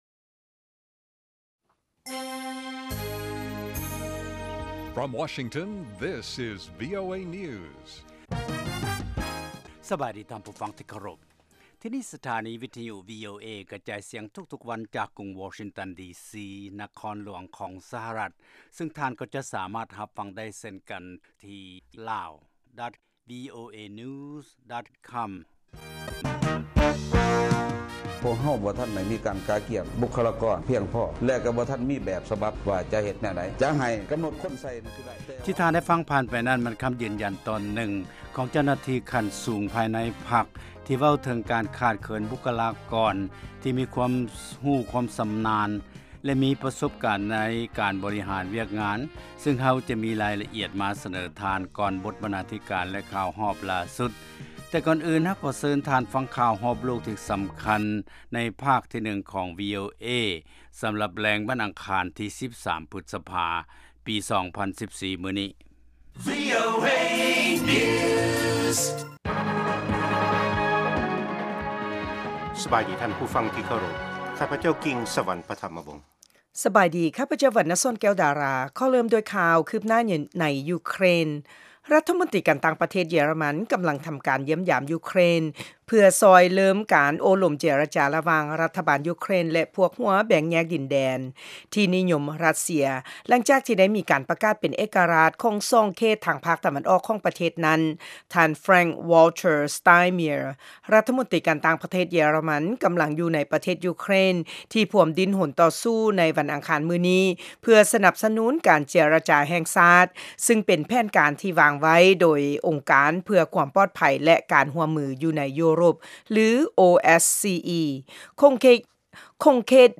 ພວກເຮົາສະເໜີຂ່າວ ຂໍ້ມູນ ແລະລາຍງານທີ່ໜ້າສົນໃຈ ກ່ຽວກັບເຫດການທີ່ເກີດຂຶ້ນໃນປະເທດລາວ ສະຫະລັດອາເມຣິກາ ເອເຊຍແລະຂົງເຂດອື່ນໆຂອງໂລກ ຕະຫລອດທັງບົດຮຽນພາສາອັງກິດ ແລະລາຍການເພງຕາມຄຳຂໍຂອງທ່ານຜູ້ຟັງ. ຕາລາງເວລາອອກອາກາດ ທຸກໆມື້ ເວລາທ້ອງຖິ່ນໃນລາວ 07:30 ໂມງແລງ ເວລາສາກົນ 1230 ຄວາມຍາວ 00:30:00 ຟັງ: MP3